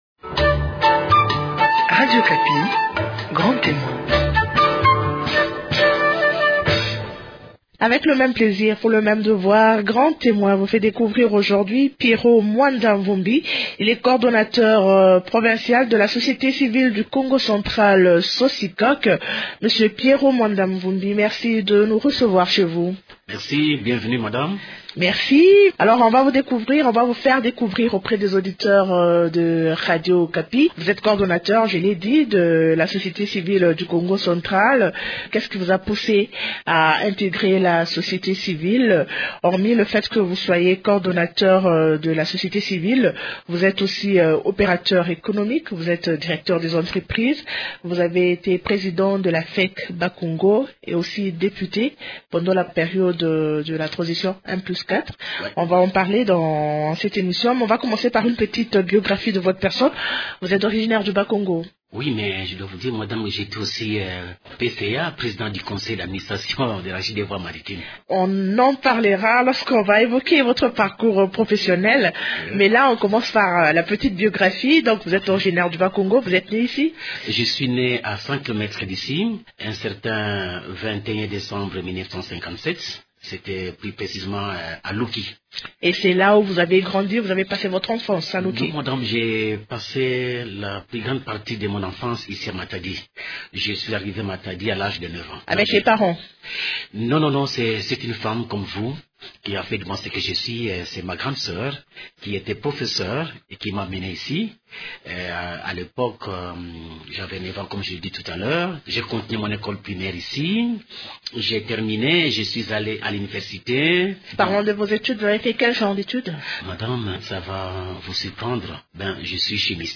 Découvrez aussi dans cet entretien sa passion pour le sport et la musique.